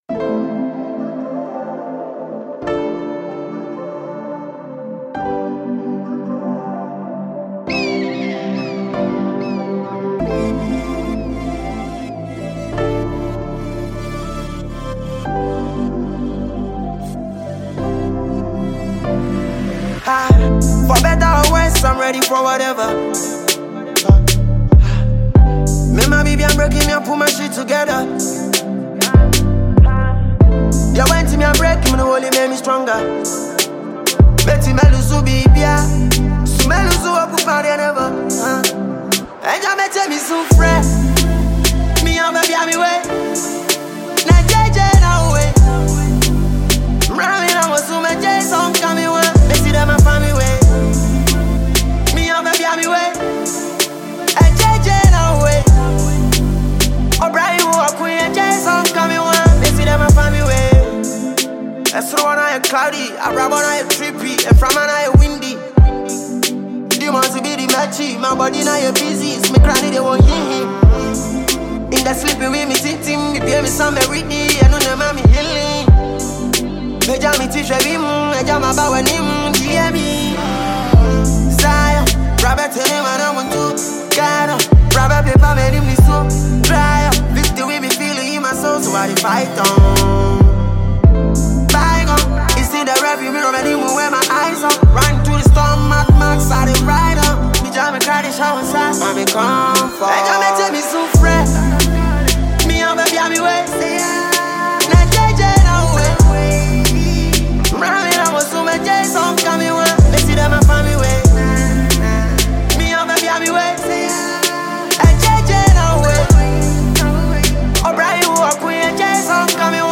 Ghanaian rapper and singer